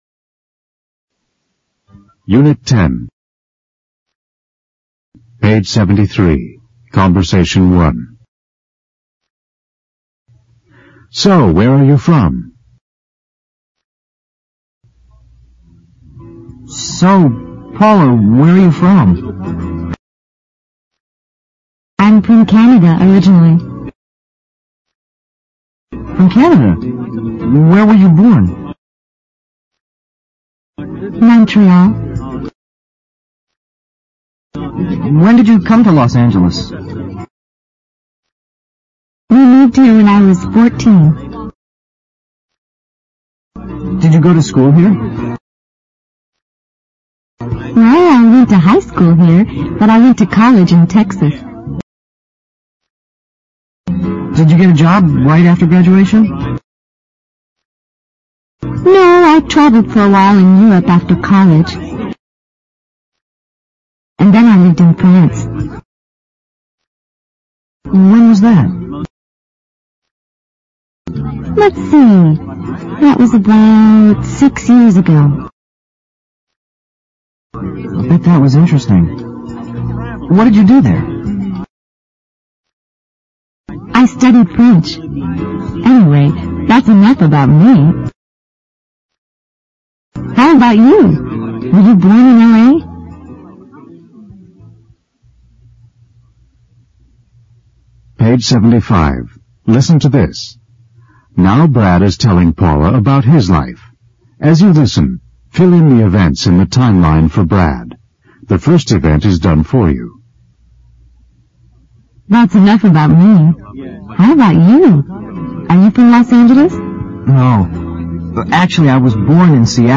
简单英语口语对话 unit10_conbersation1_new(mp3+lrc字幕)